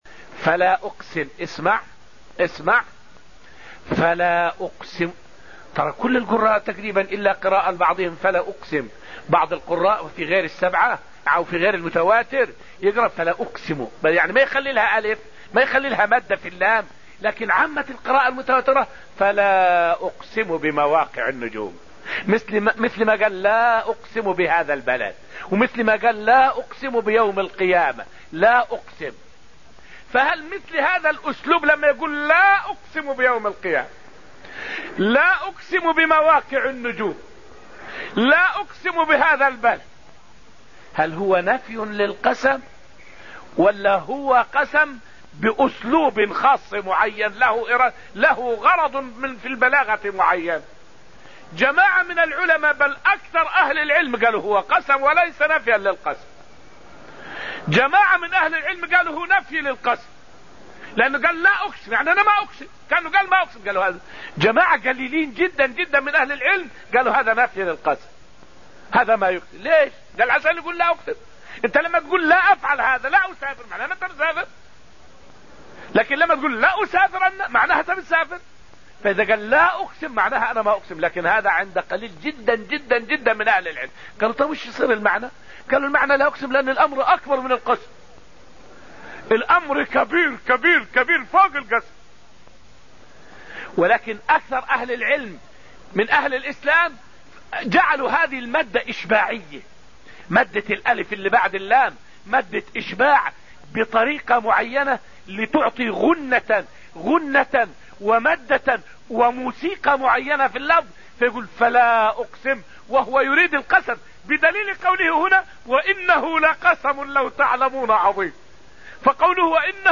فائدة من الدرس السادس من دروس تفسير سورة الواقعة والتي ألقيت في المسجد النبوي الشريف حول الغرض البلاغي من نفي القسم وهو يريد القسم.